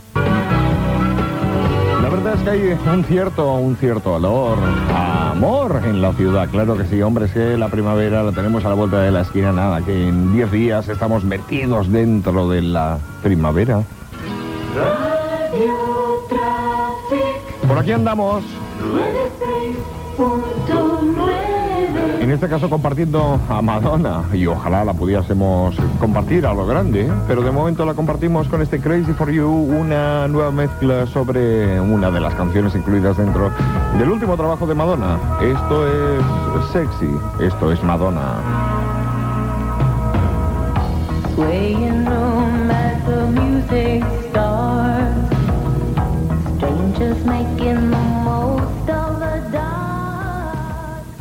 Indicatiu i tema musical. Gènere radiofònic Musical